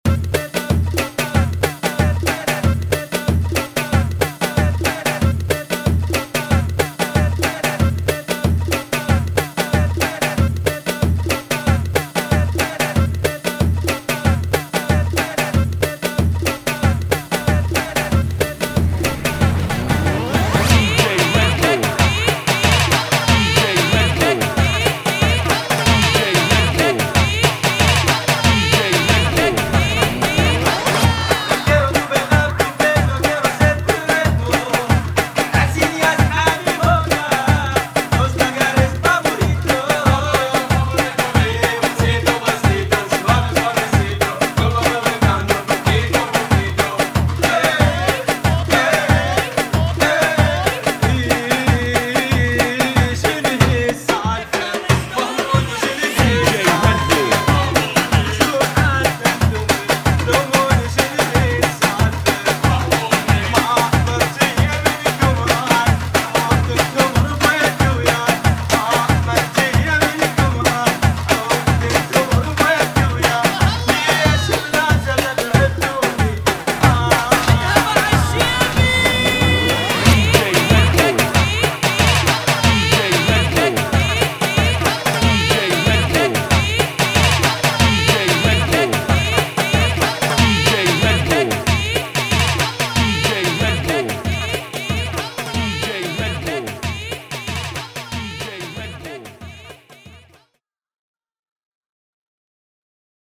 [ 93 bpm ]